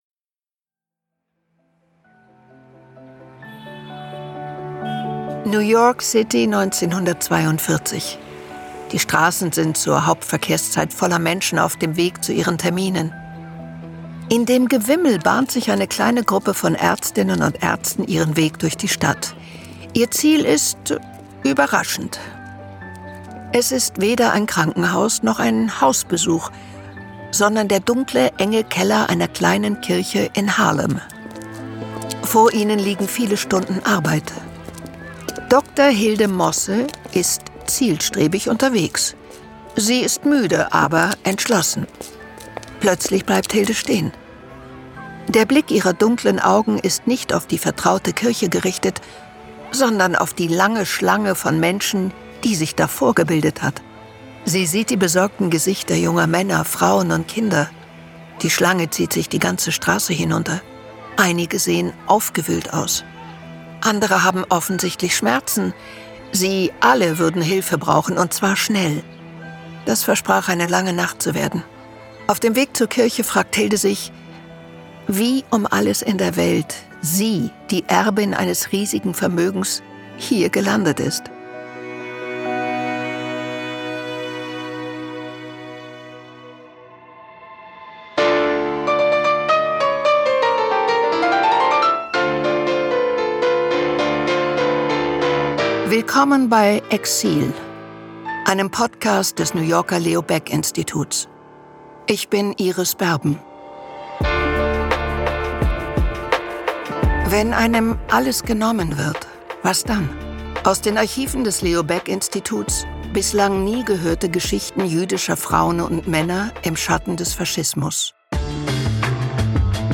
Sprecherin: Iris Berben